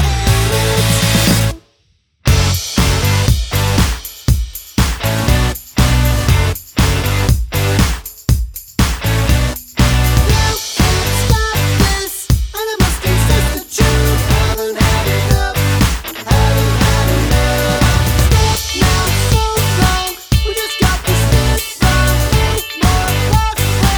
no Backing Vocals For Guitarists 3:34 Buy £1.50